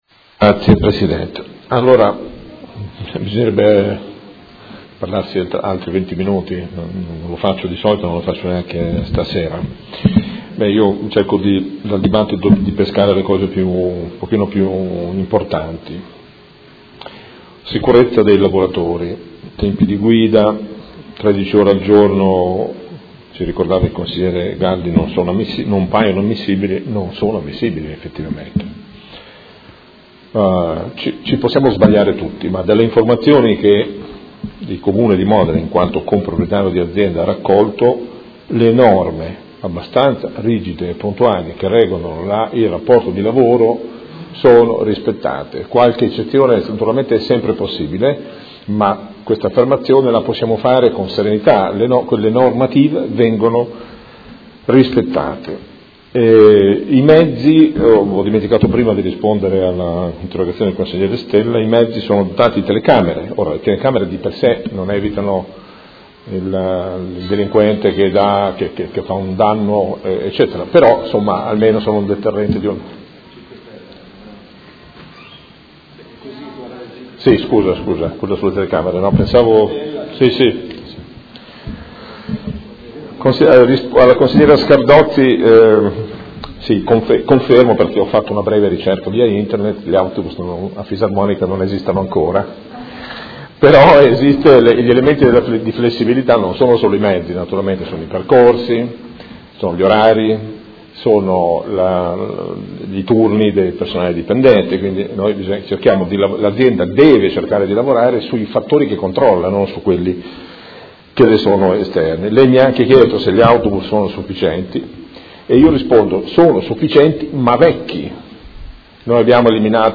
Gabriele Giacobazzi — Sito Audio Consiglio Comunale
Seduta del 27/04/2017 Conclusioni.